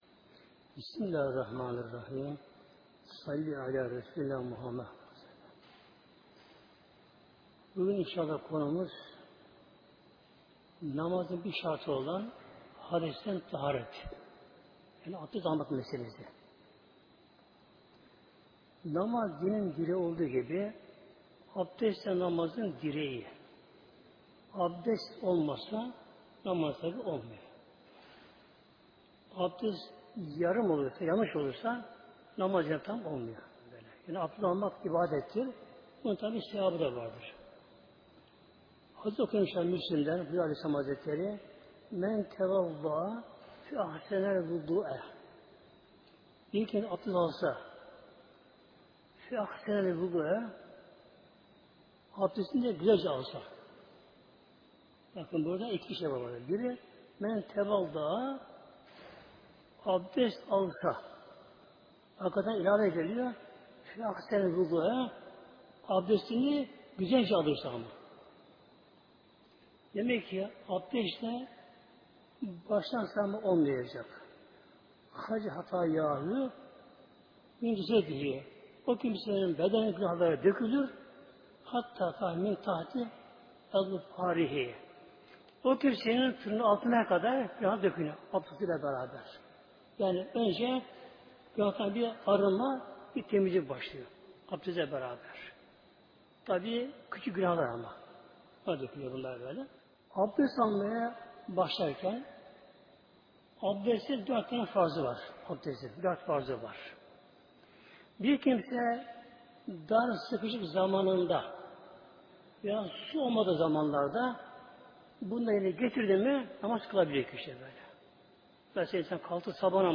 Sohbet